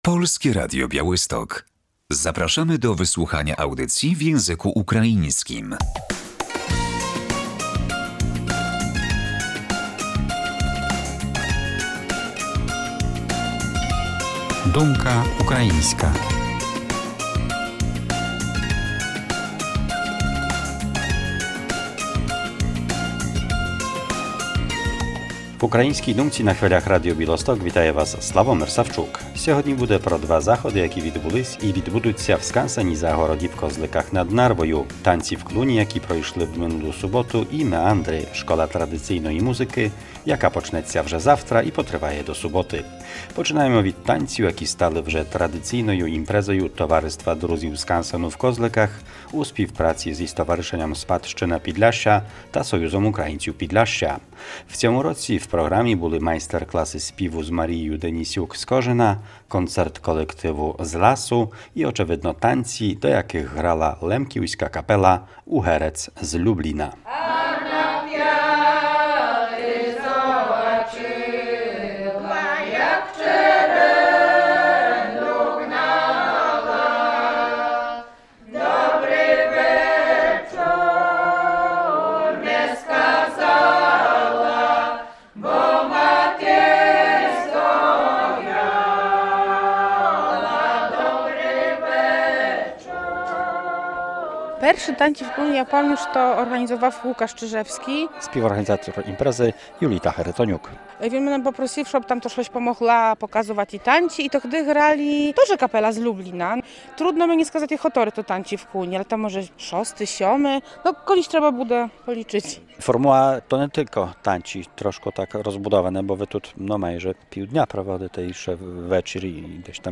Warsztaty pieśni, koncert , potańcówka i okazja do spotkania w malowniczym miejscu – oto atrakcje „Tańców w Kłuni”, które odbyły się w Skansenie Zagrodzie w Koźlikach nad Narwią.